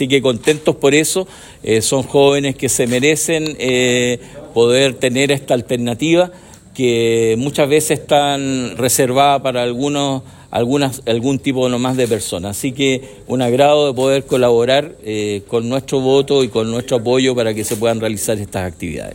La iniciativa busca fomentar el desarrollo integral de los alumnos, ofreciéndoles la posibilidad de explorar nuevas realidades y fortalecer su educación a través de estos viajes, que contribuyen tanto a nivel académico como personal, según indicó el Concejal Castilla.